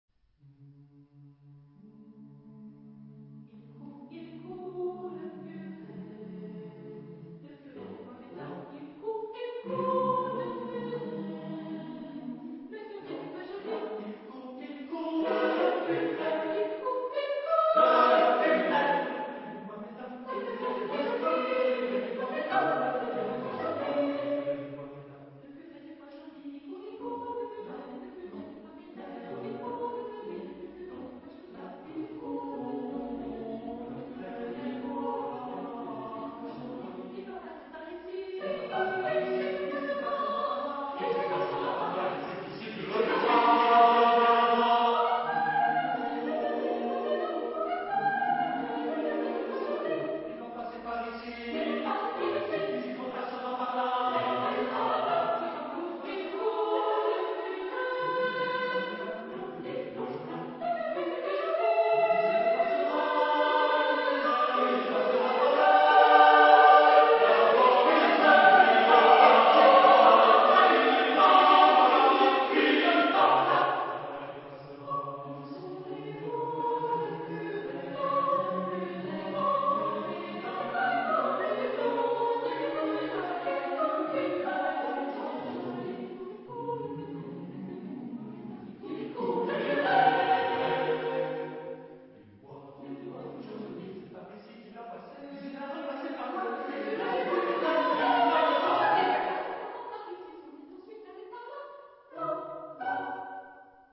Genre-Stil-Form: Liedsatz ; Volkstümlich ; weltlich
Chorgattung: SATB  (4 gemischter Chor Stimmen )
Tonart(en): G-Dur
Lokalisierung : Populaire Francophone Acappella